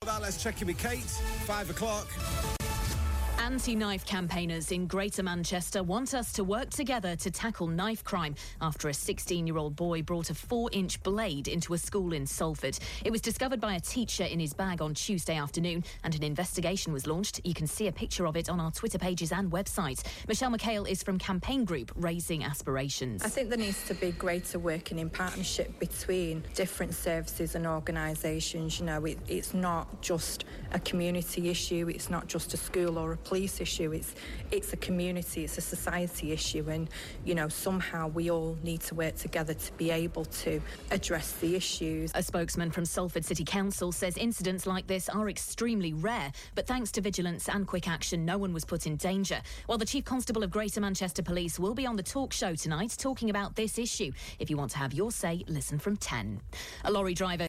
Campaigner talks to Key news after school boy takes knife into school